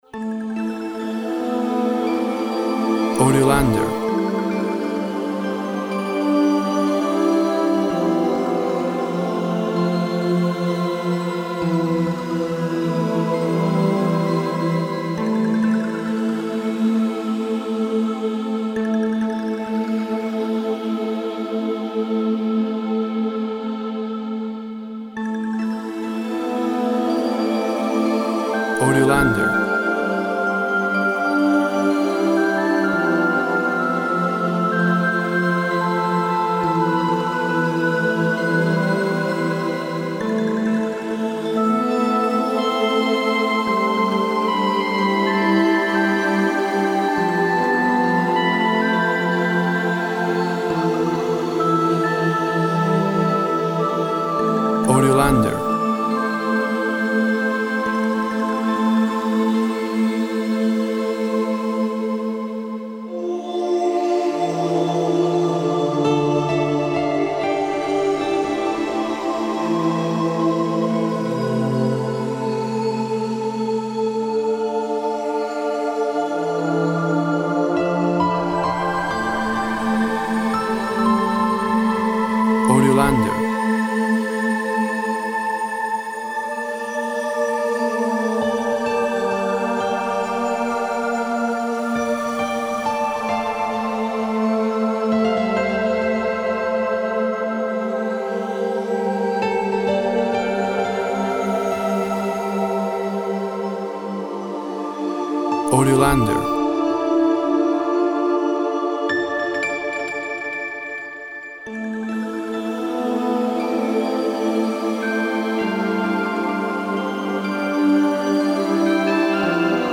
Soft mix of instruments creates a brooding atmosphere.
Tempo (BPM) 60